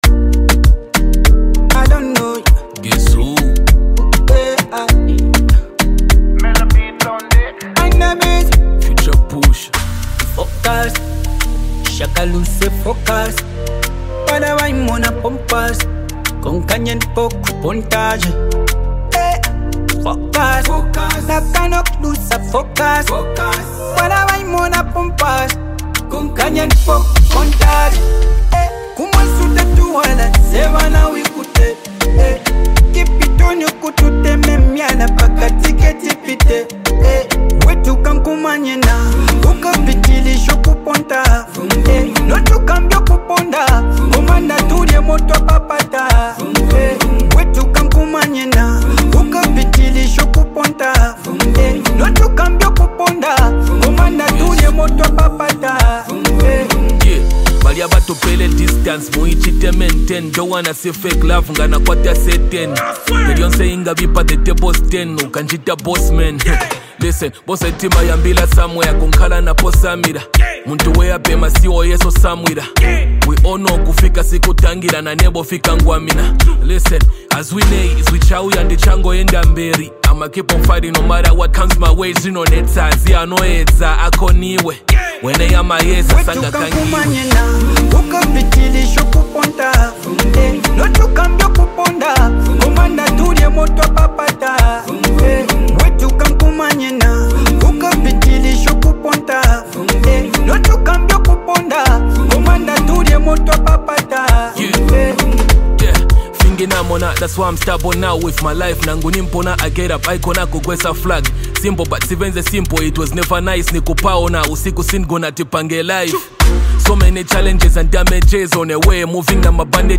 contemporary R&B